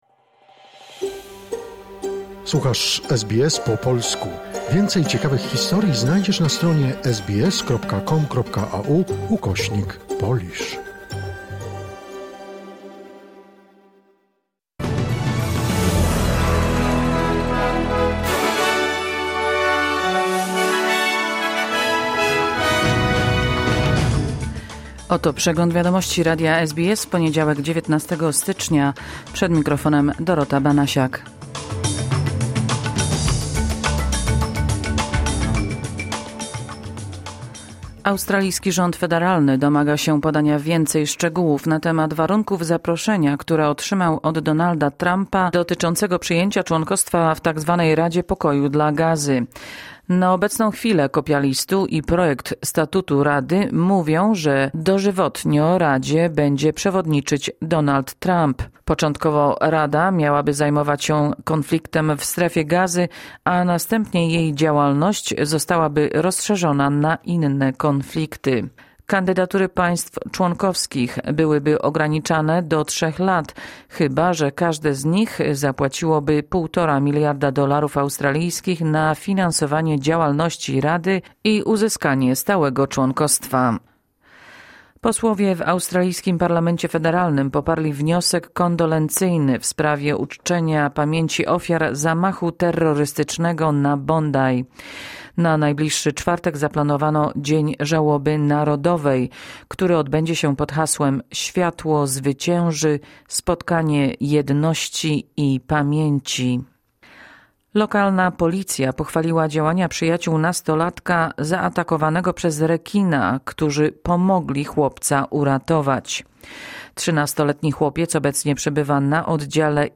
Wiadomości 19 stycznia 2026 SBS News Flash